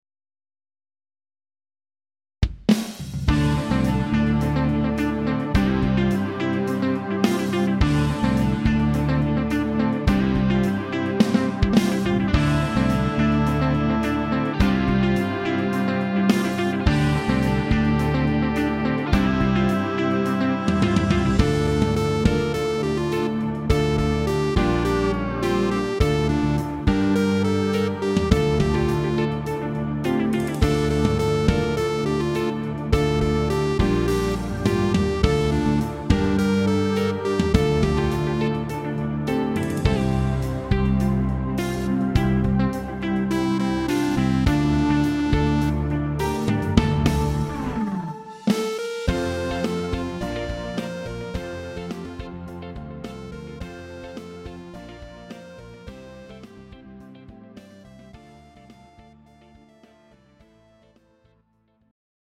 Greek Rock